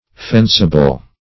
Meaning of fencible. fencible synonyms, pronunciation, spelling and more from Free Dictionary.
Search Result for " fencible" : The Collaborative International Dictionary of English v.0.48: Fencible \Fen"ci*ble\, a. Capable of being defended, or of making or affording defense.